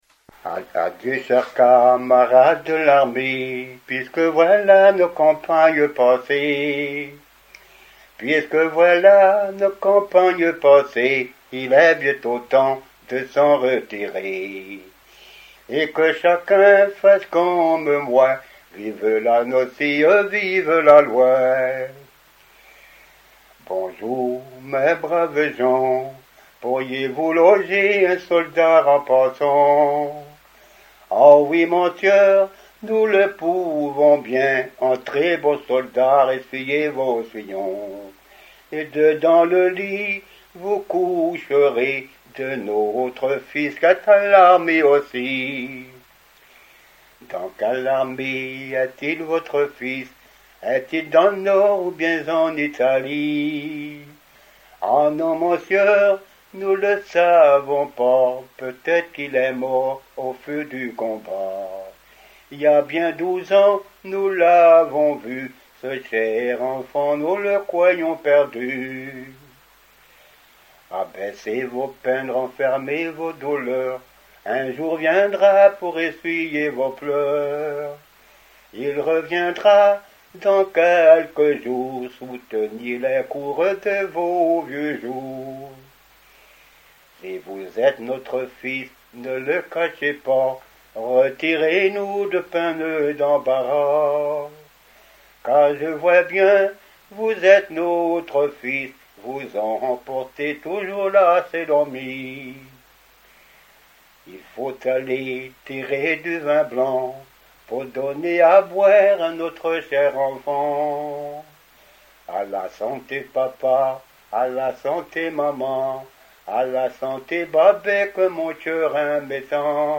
Chansons traditionnelles et populaires
Pièce musicale inédite